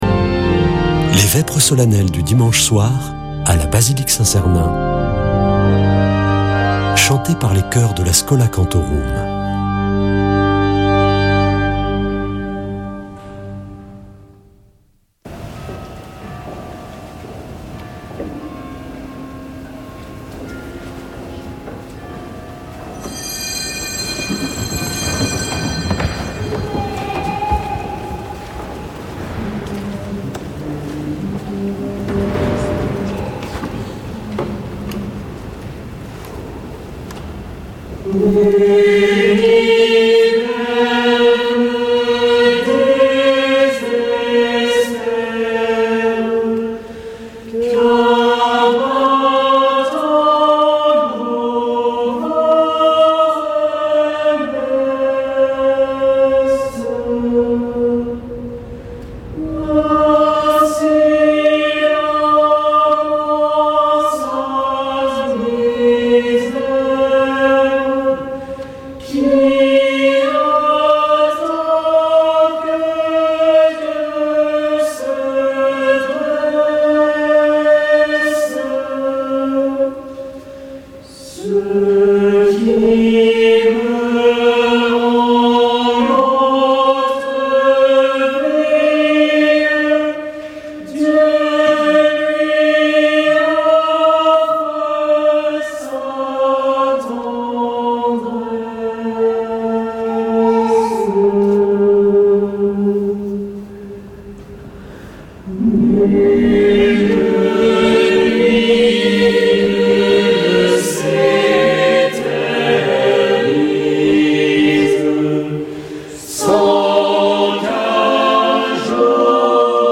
Vêpres de Saint Sernin du 26 mars
Schola Saint Sernin Chanteurs